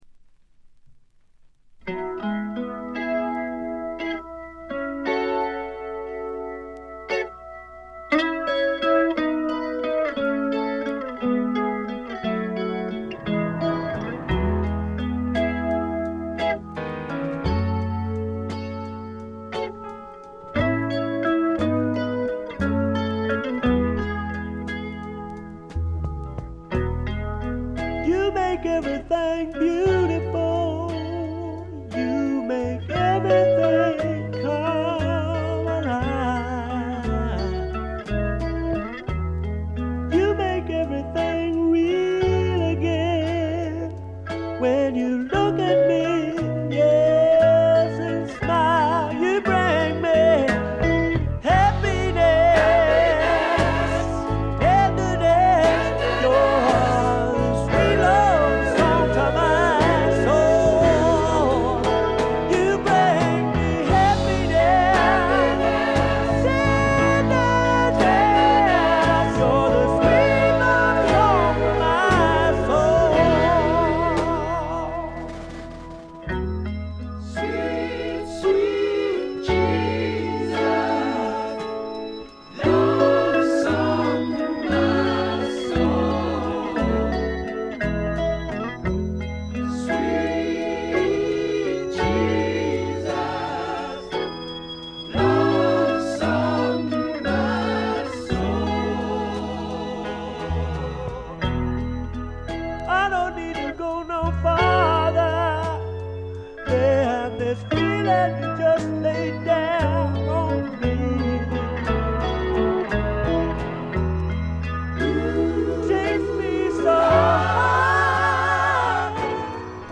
わずかなノイズ感のみ。
泣けるバラードからリズムナンバーまで、ゴスペル風味にあふれたスワンプロック。
試聴曲は現品からの取り込み音源です。